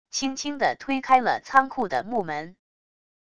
轻轻地推开了仓库的木门wav音频